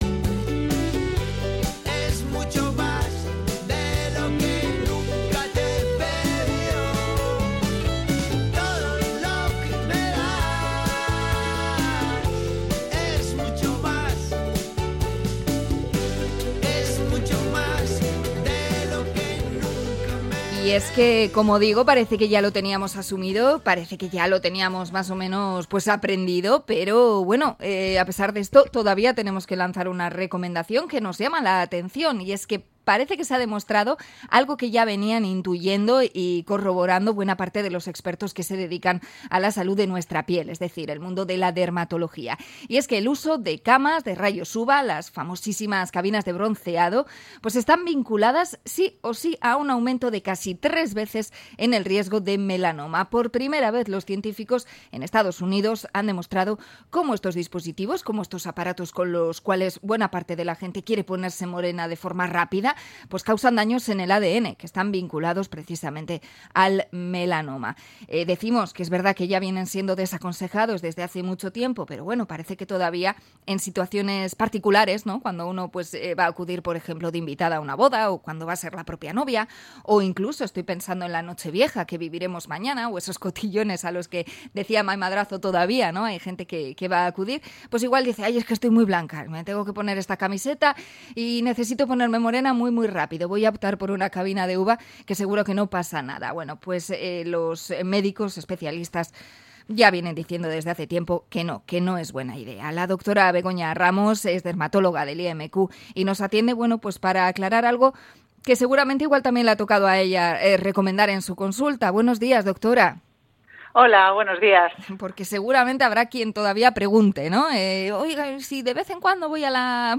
Entrevista a dermatóloga por los rayos UVA para ponerse moreno